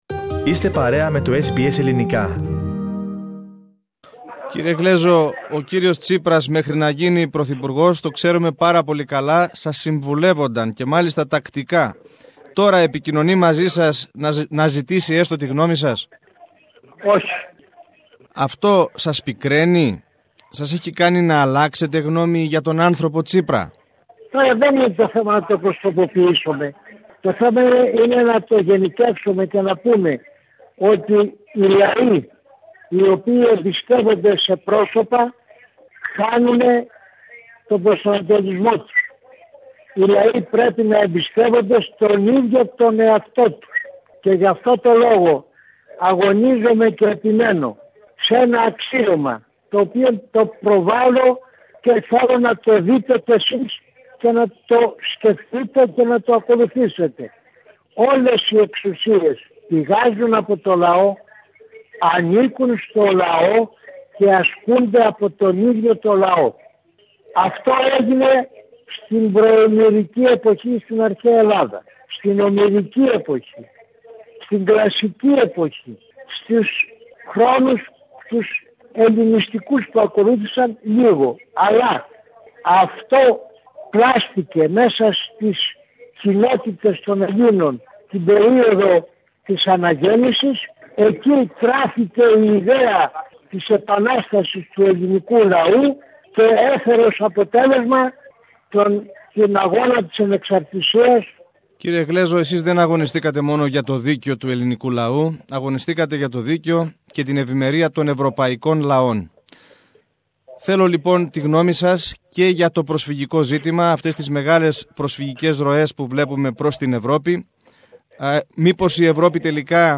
In the audio provided here, we listen the interview that Manolis Glezos gave to the Greek Program of SBS Radio in March 2016.